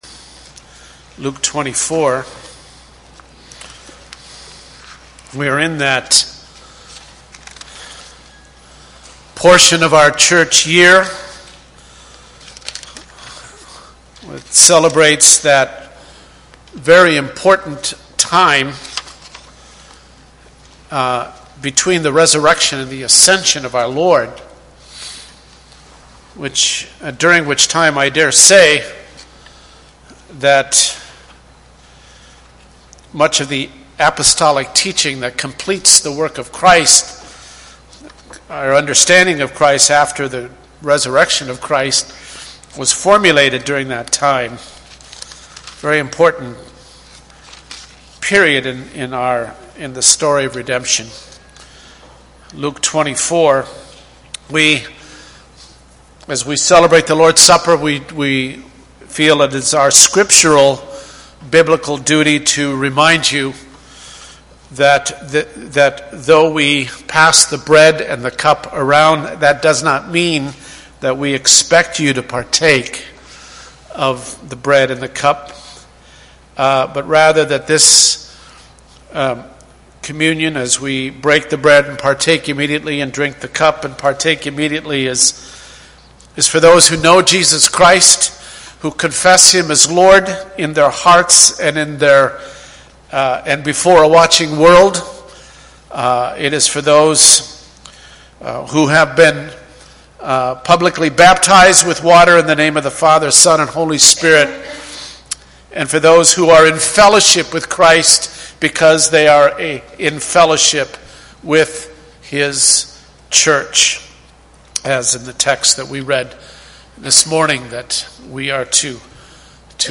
Communion Homily: It Was Necessary for Him to Die and Rise Again
Service Type: Sunday Morning